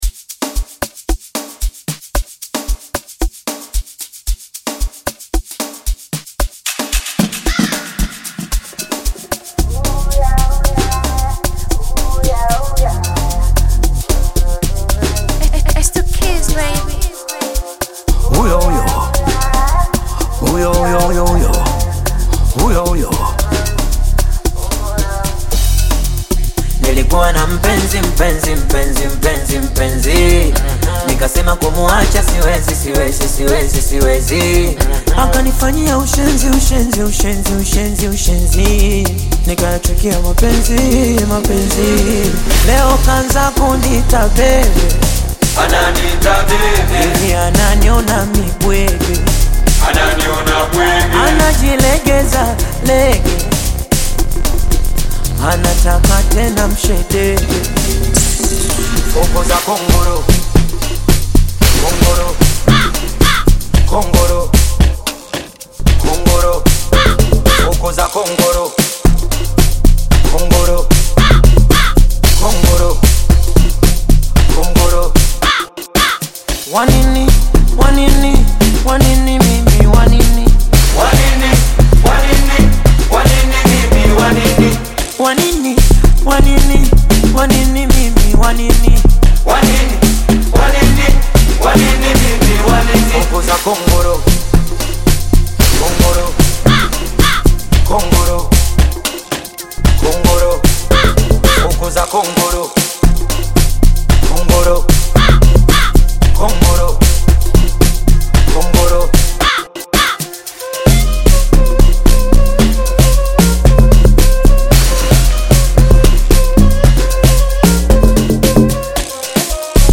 Tanzanian singer and songwriter
Amapiano track
heartfelt track